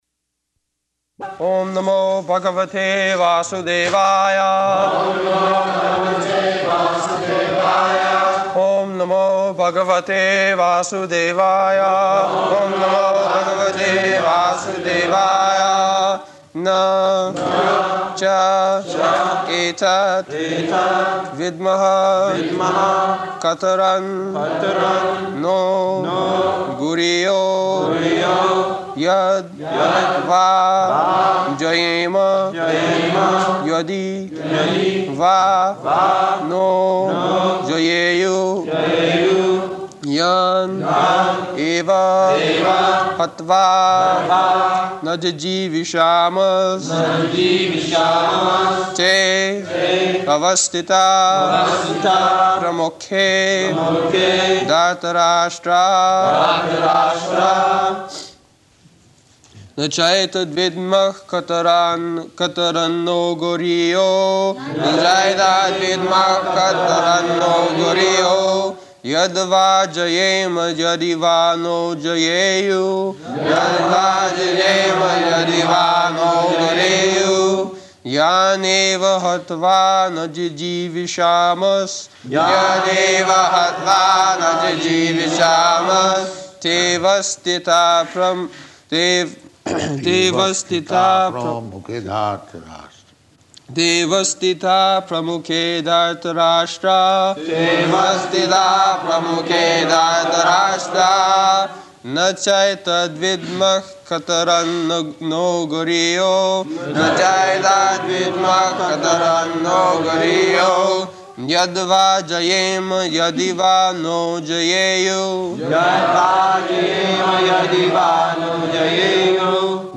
August 6th 1973 Location: London Audio file
[leads chanting of verse] [Prabhupāda and devotees repeat]
[Prabhupāda corrects pronunciation of last line]